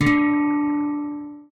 guitar_d.ogg